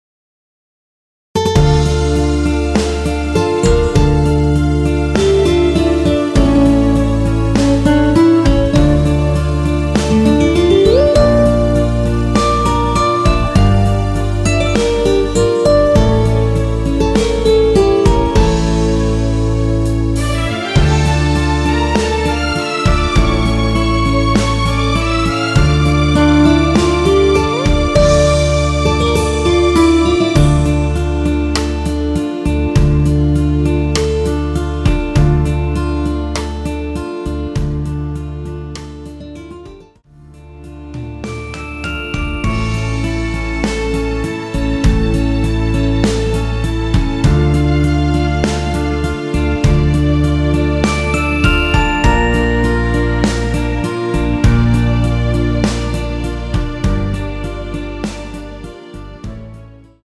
Dm
앞부분30초, 뒷부분30초씩 편집해서 올려 드리고 있습니다.
중간에 음이 끈어지고 다시 나오는 이유는